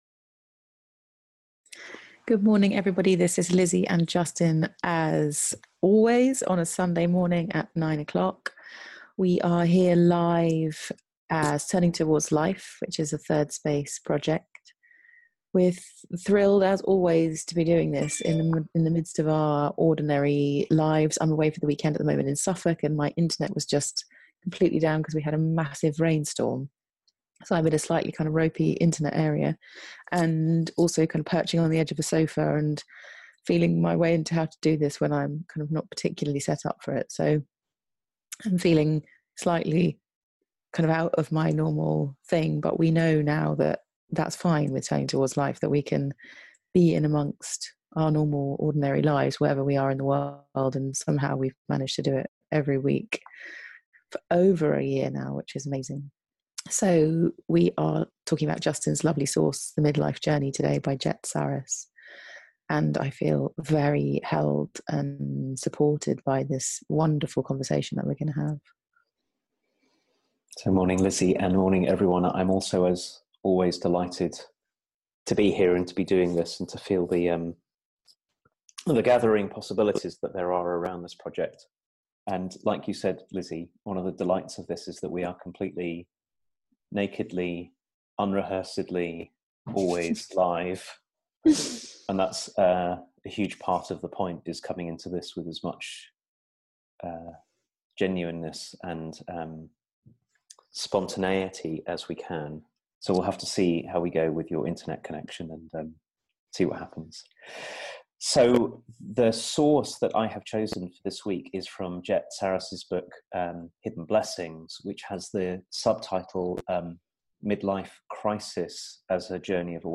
A conversation about mid-life transitions and transformation